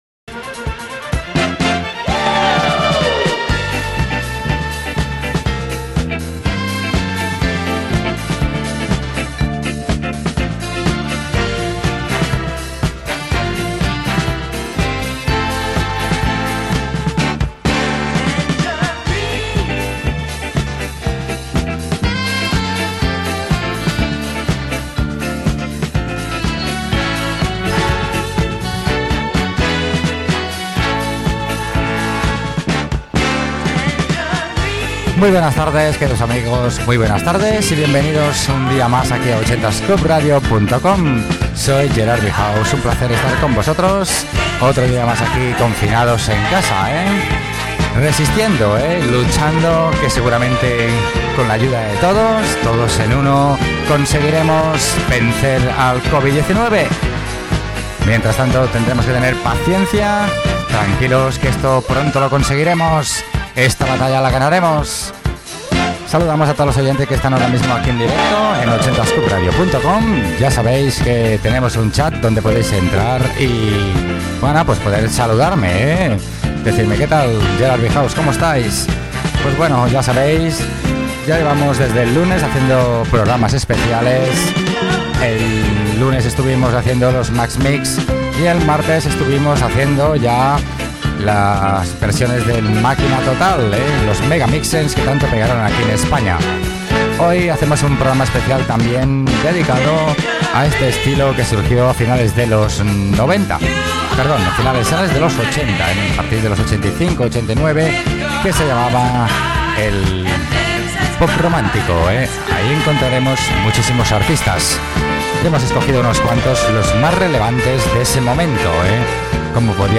Salutació en l'inici del programa durant el confinament degut a la pandèmia de la Covid 19, programa dedicat al pop romàntic de la dècada de 1980, indicatiu del programa i tema musical
Musical